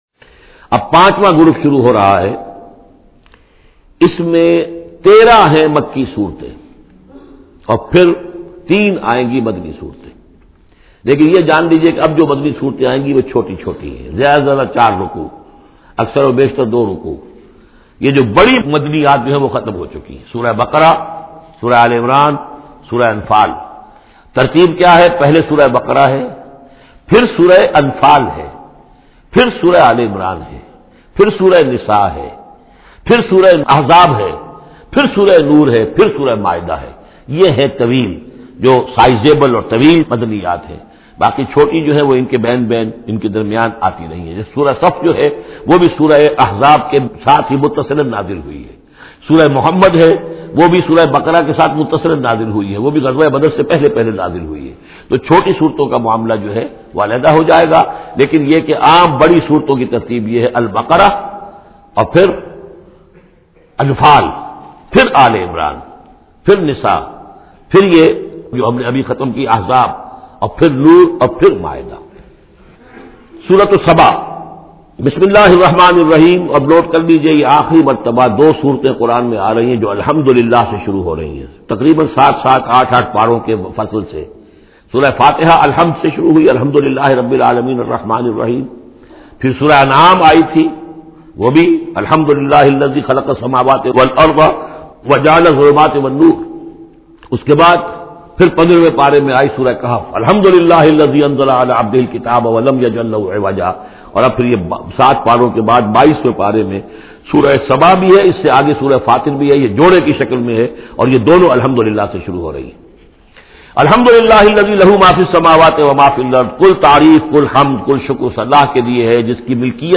Surah Saba Urdu Tafseer by Dr Israr Ahmed
Surah is 34 chapter of Holy Quran. Listen audio tafseer in the voice of Dr Israr Ahmed.